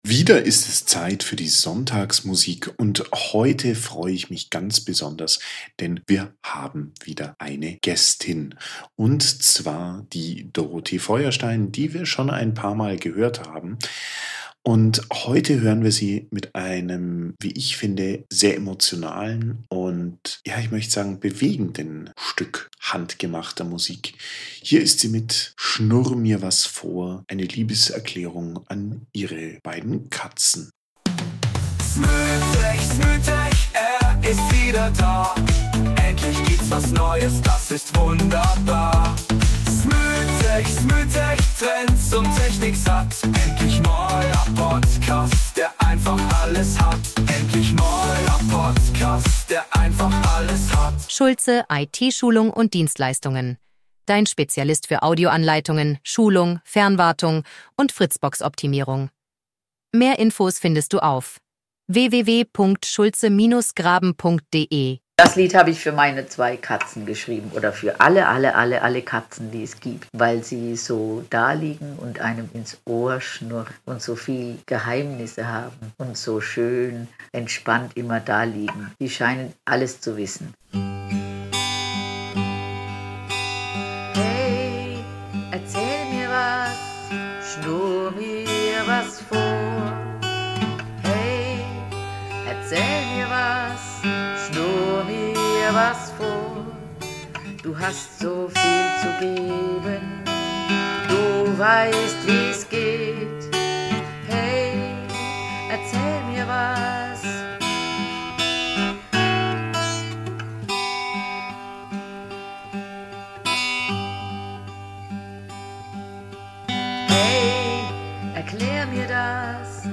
und uns mit einem Stück handgemachter Sonntagsmusik über ihre